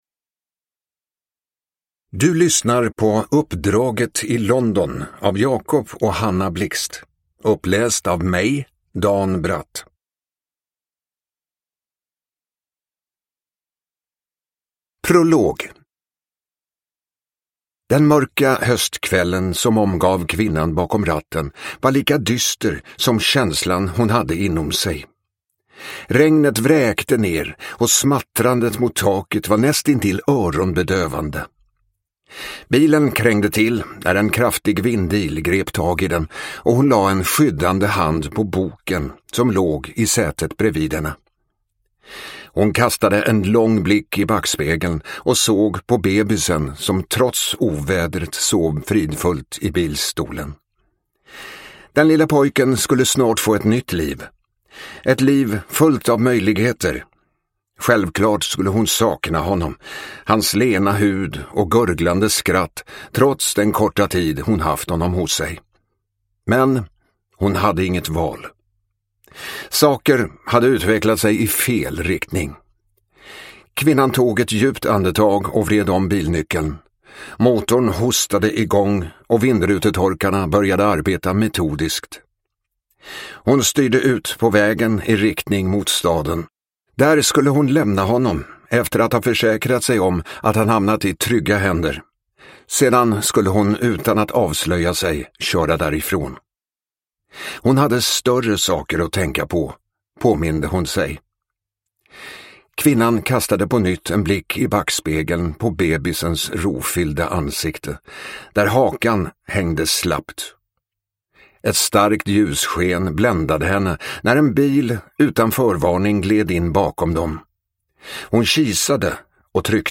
Uppdraget i London (ljudbok) av Hanna Blixt